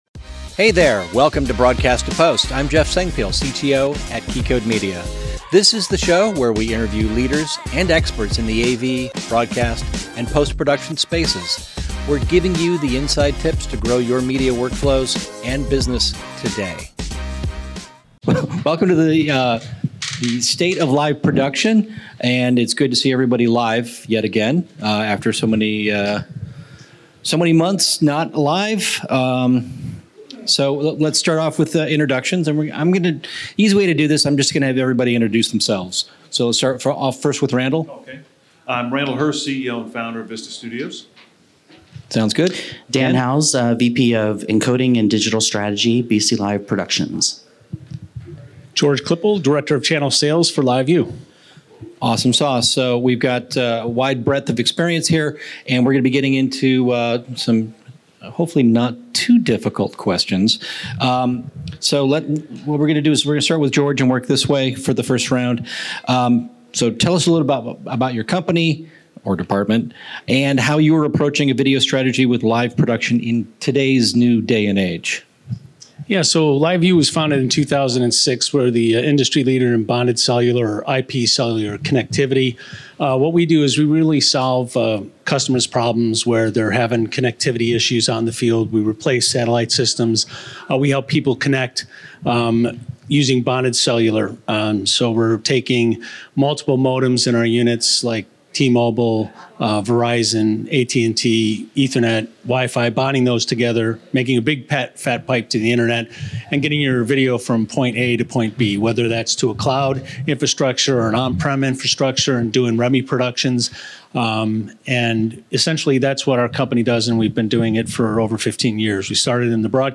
This is a live recording from our PostNAB LA event.